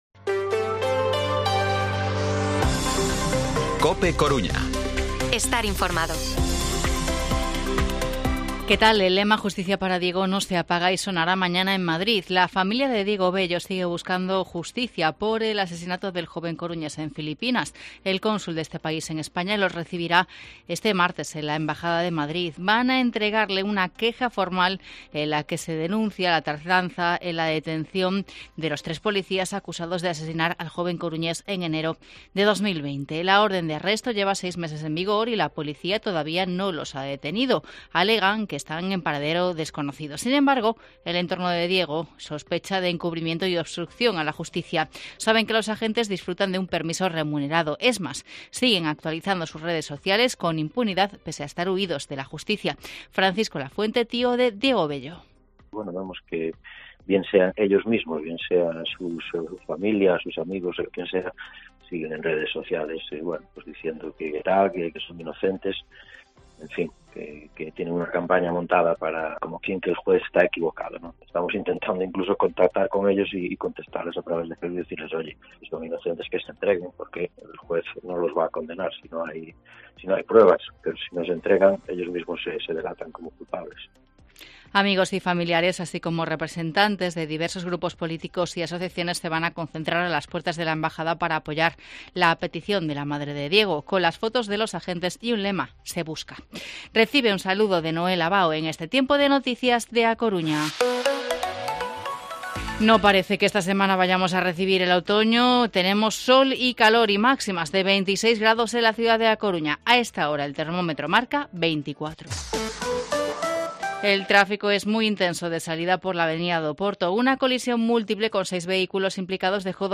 Informativo Mediodía COPE Coruña lunes, 19 de septiembre de 2022, 14:20-14:30